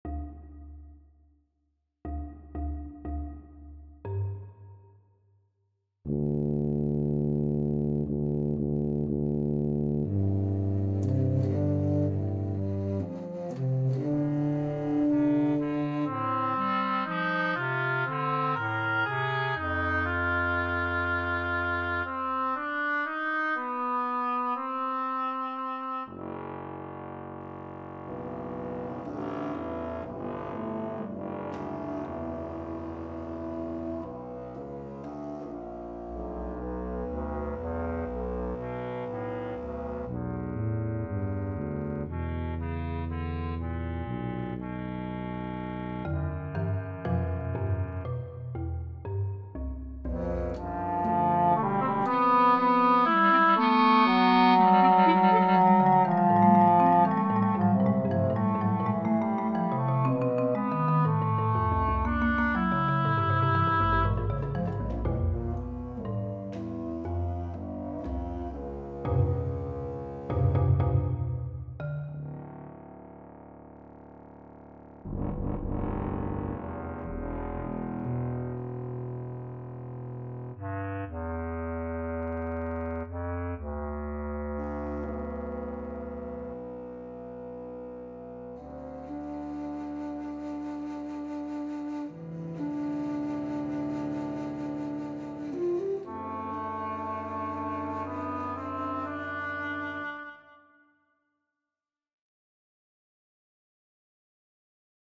- Chamber Music - Young Composers Music Forum